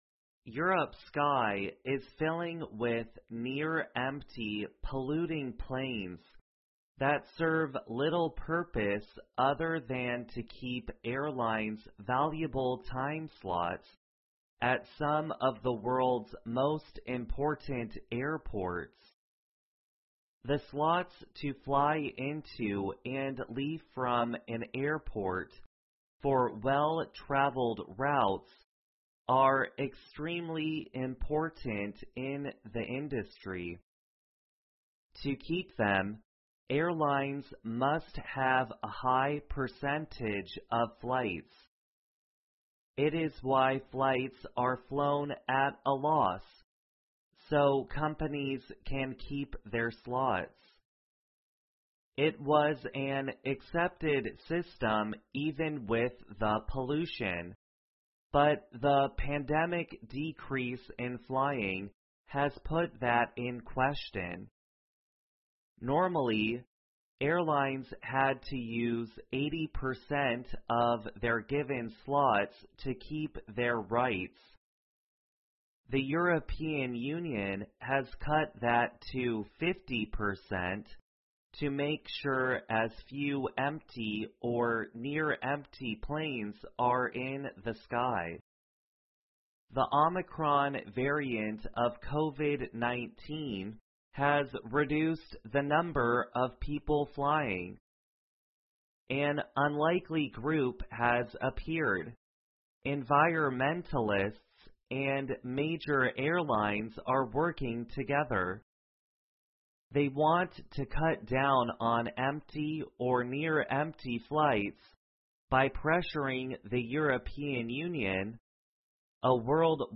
VOA慢速英语2021 欧盟空载航班满天飞以保住起降时段 听力文件下载—在线英语听力室